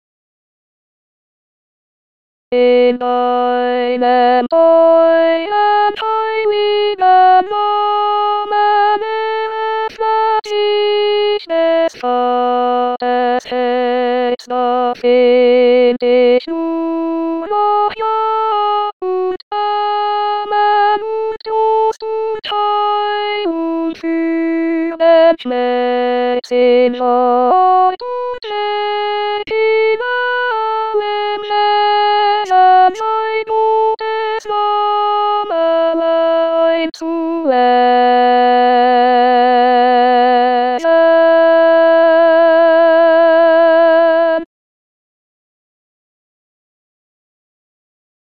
ich bete an die Macht der Liebe hautes contre.mp3